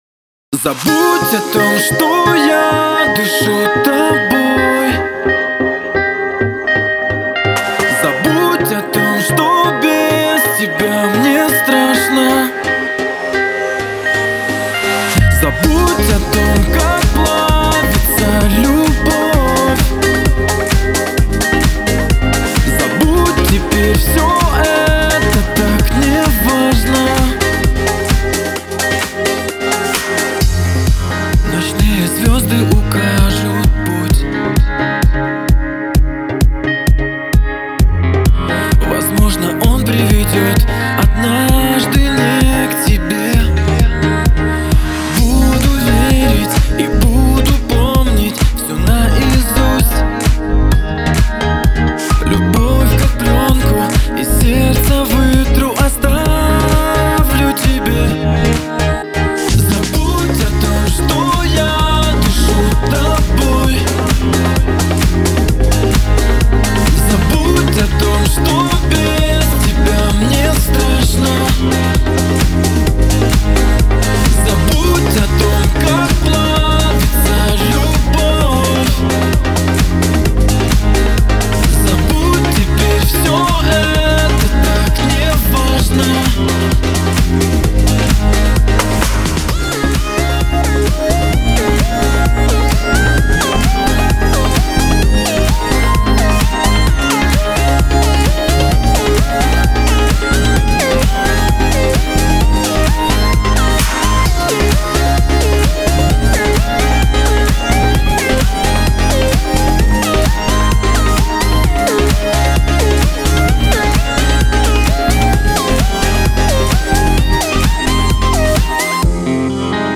Категория: Грустные песни